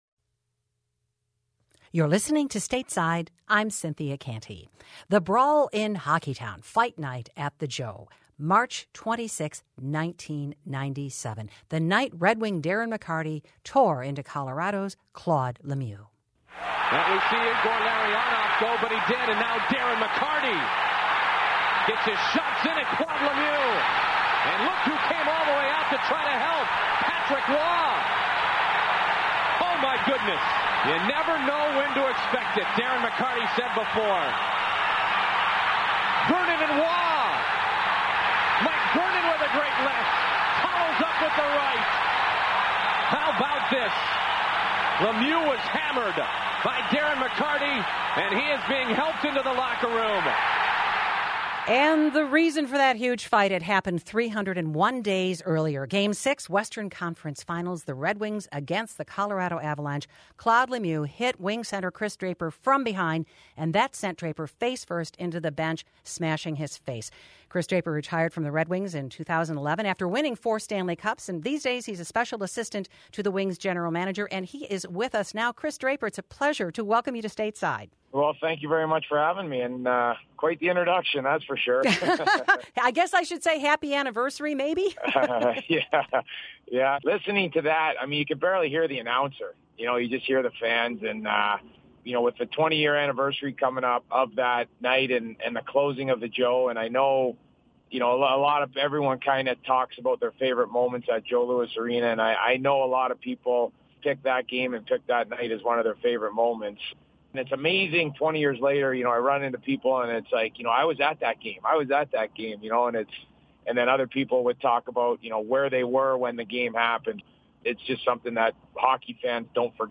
Stateside's conversation with former Detroit Red Wings player Kris Draper.
Listen to the full interview above to hear Draper describe the hit he suffered, how the brawl got started almost a year later and his favorite memories from his 17 years of playing for the Red Wings at Joe Louis Arena.